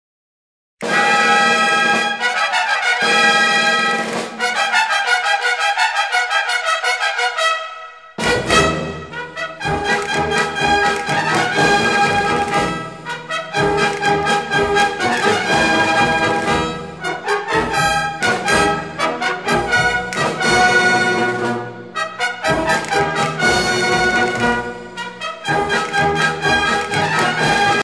danse : paso-doble
circonstance : militaire